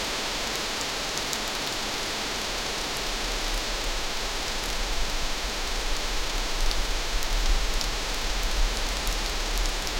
A sudden crack of lightning splits the sky, echoing like a scream. 0:10 Regnerisches Wetter (z.B. starke Regengeräusche) 0:10 Heavy Rain, water splash on windows, some thunders 0:15
regnerisches-wetter-zb-st-pwyvefyw.wav